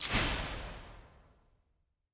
sfx-eog-ui-gold-burst.ogg